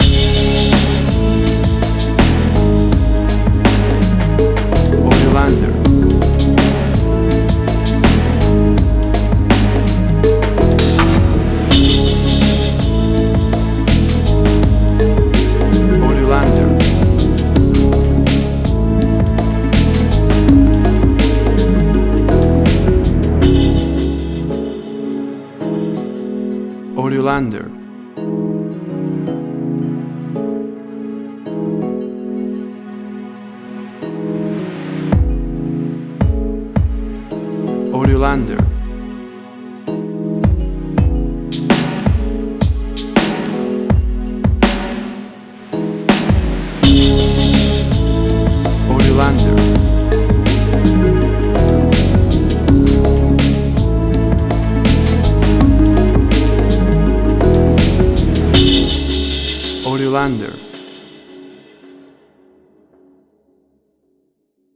WAV Sample Rate: 24-Bit stereo, 44.1 kHz
Tempo (BPM): 82